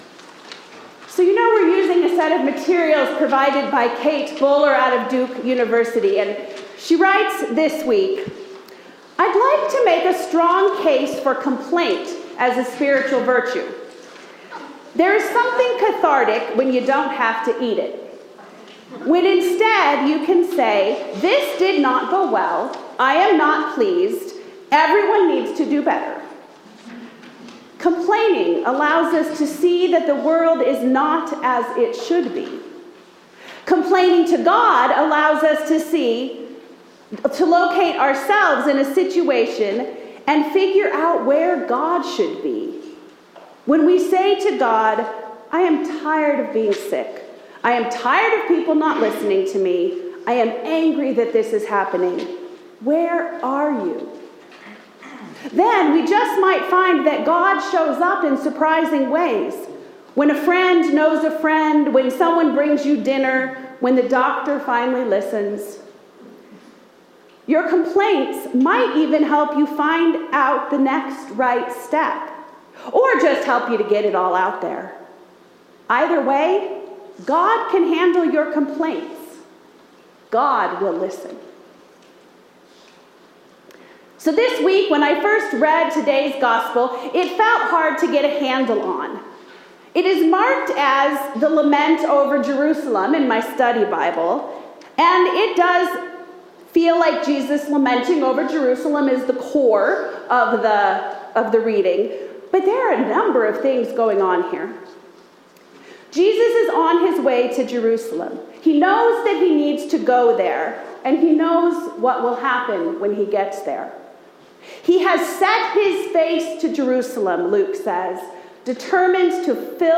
Sermons – Page 2 – All Saints Lutheran Church, ELCA
John 8:2-11 Service Type: Sunday Morning